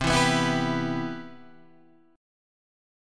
C Major 7th
CM7 chord {x 3 2 0 0 0} chord